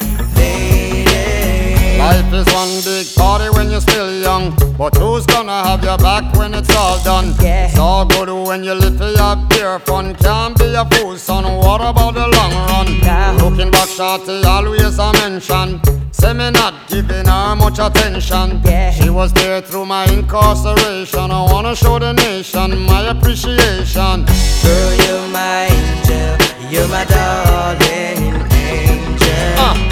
• Reggae